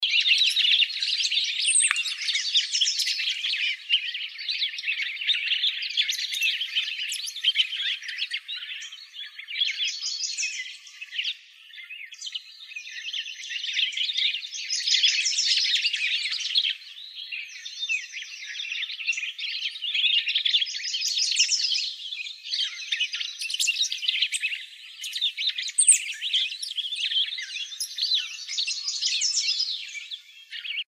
cassinsfinch.wav